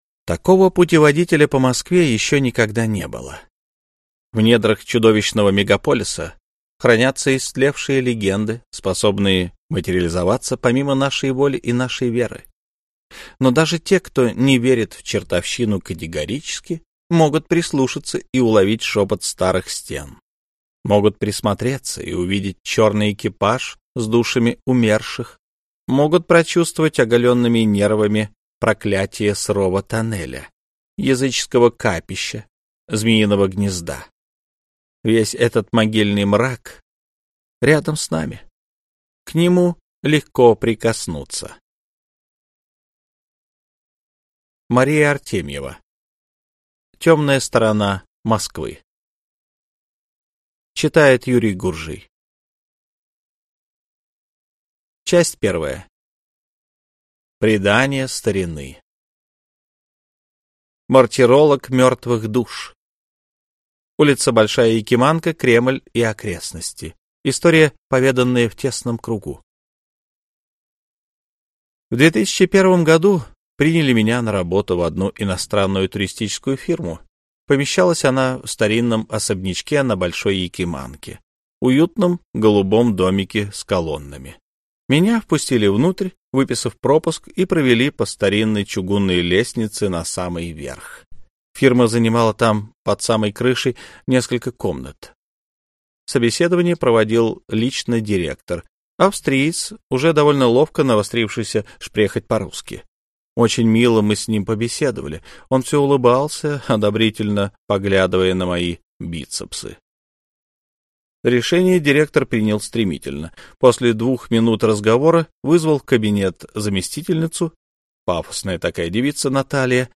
Аудиокнига Темная сторона Москвы | Библиотека аудиокниг